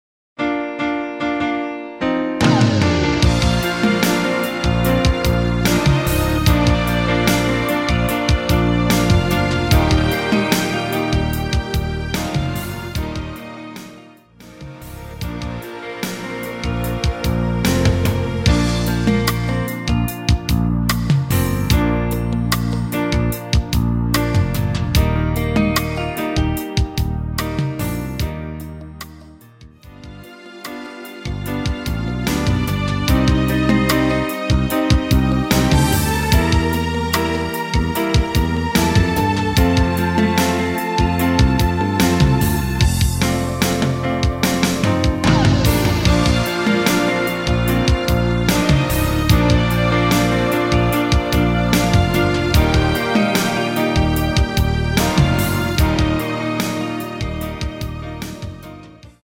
-2)내린 MR입니다. 발매일 2000.12 키 Bb 가수
원곡의 보컬 목소리를 MR에 약하게 넣어서 제작한 MR이며